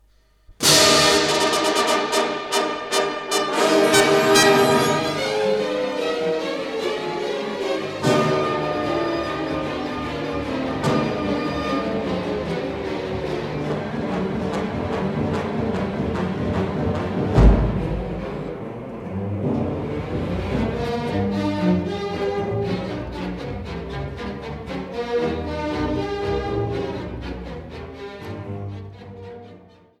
Molto vivace